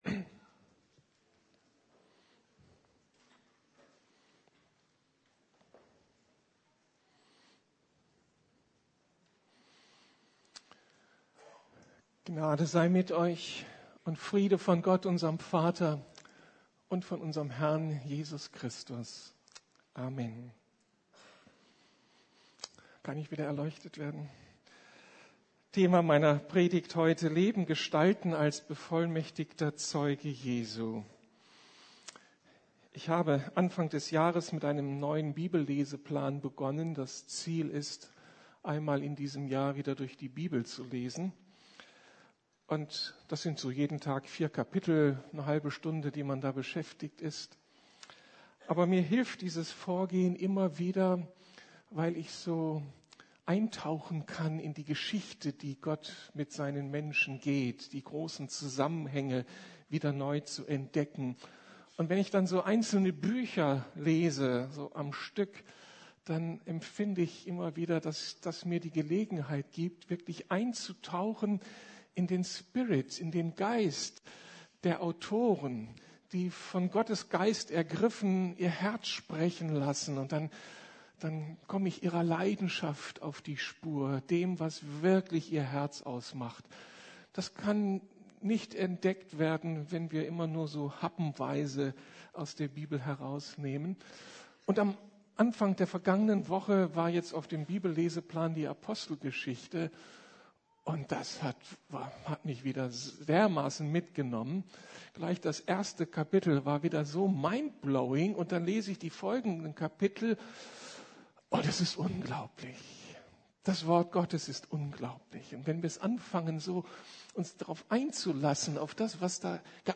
Leben gestalten - als bevollmächtigter Zeuge Christi ~ Predigten der LUKAS GEMEINDE Podcast